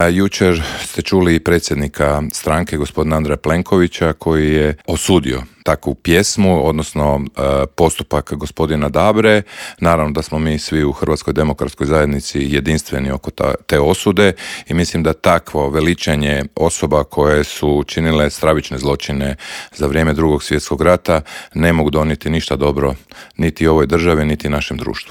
ZAGREB - U Intervjuu Media servisa gostovao je predsjednik zagrebačkog HDZ-a Ivan Matijević s kojim smo prošli aktualne teme na nacionalnoj, kao i na zagrebačkoj razini.